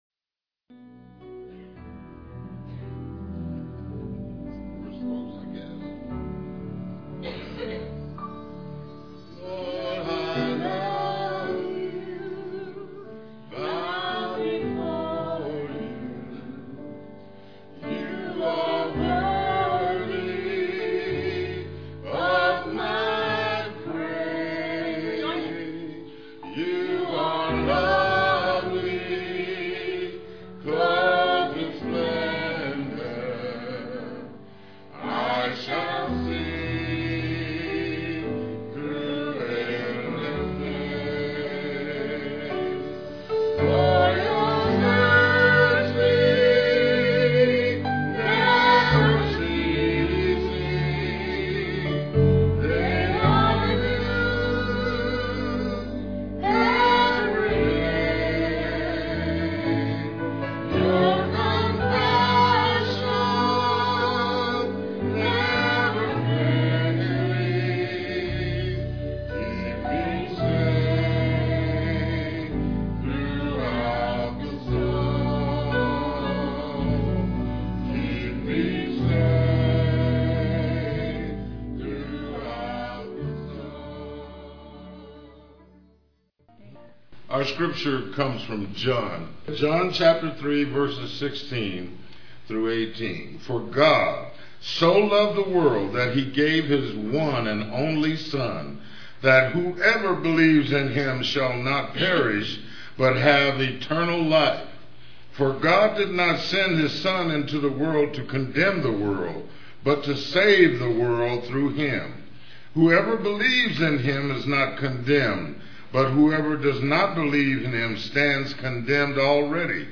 Scripture reading and message
"In the Garden" guitar and vocal
"The Savior Is Waiting" vocal solo
Piano and organ duet